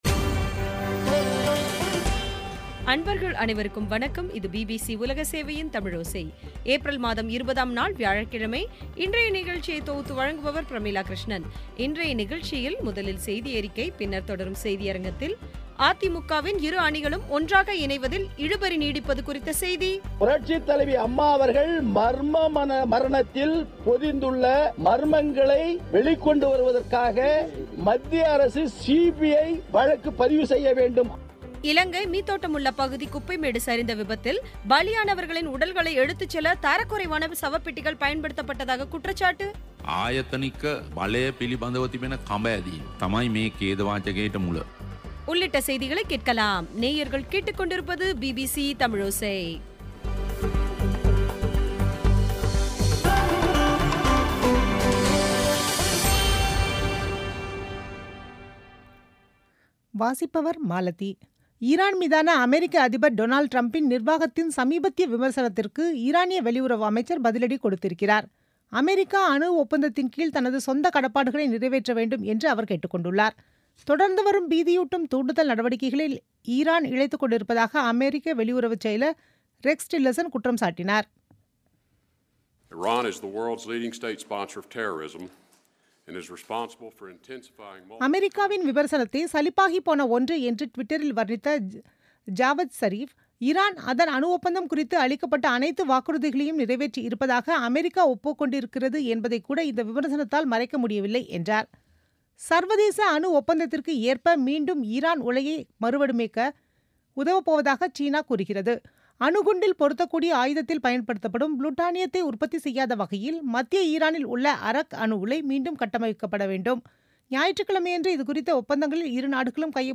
இன்றைய நிகழ்ச்சியில் முதலில் செய்தியறிக்கை, பின்னர் தொடரும் செய்தியரங்கில் அ.தி.மு.கவின் இரு அணிகளும் ஒன்றாக இணைவதில் இழுபறி நீடிப்பது குறித்த செய்தி இலங்கை மீதொட்டமுல்ல பகுதி குப்பை மேடு சரிந்த விபத்தில், பலியானவர்களின் உடல்களை எடுத்துசெல்ல தரக்குறைவான சவப்பெட்டிகள் பயன்படுத்தப்பட்டதாக குற்றச்சாட்டு உள்ளிட்டவை கேட்கலாம்